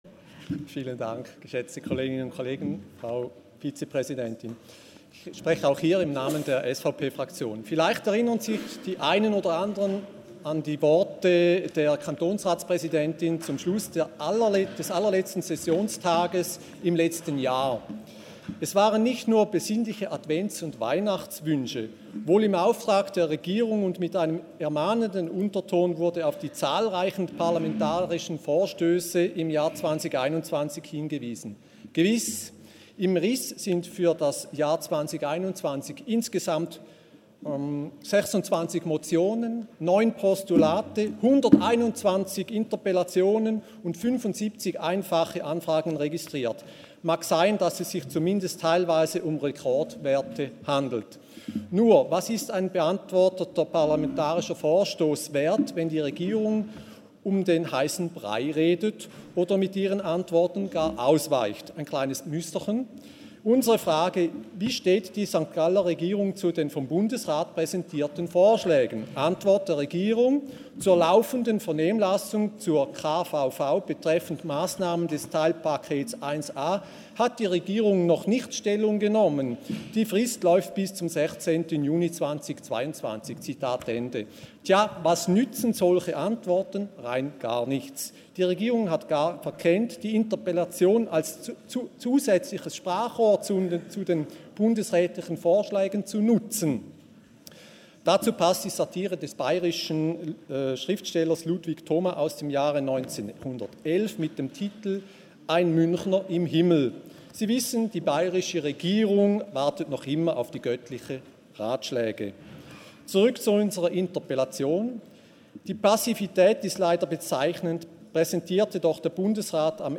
ArtKR Interpellation
Session des Kantonsrates vom 13. bis 15. Juni 2022